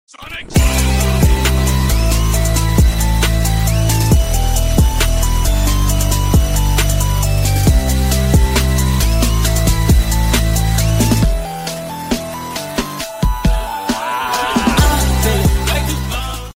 a little of beat